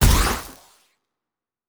Sci Fi Explosion 23.wav